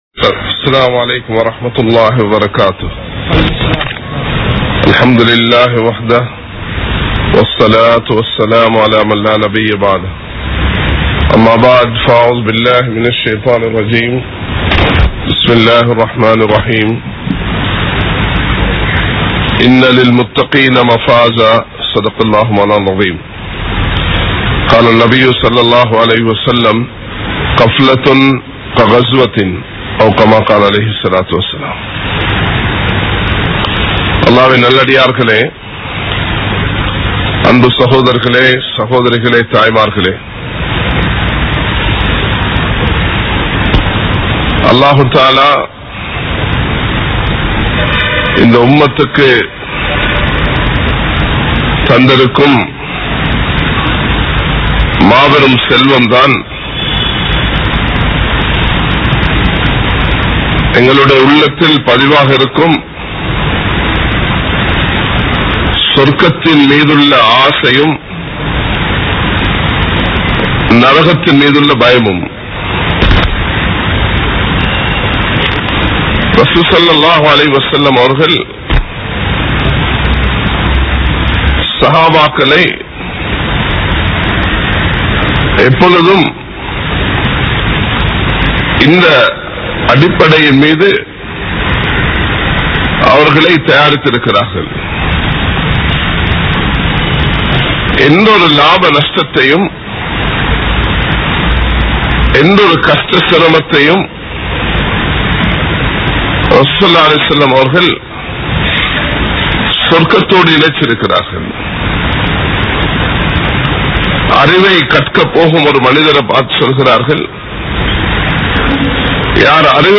Unity | Audio Bayans | All Ceylon Muslim Youth Community | Addalaichenai
Muhideen (Teru Palli) Jumua Masjith